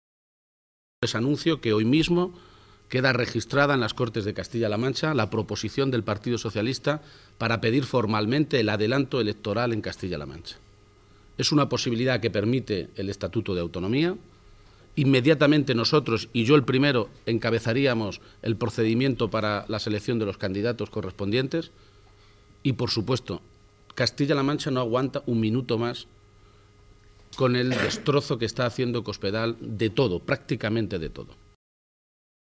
Emiliano García-Page durante el desayuno informativo celebrado en Cuenca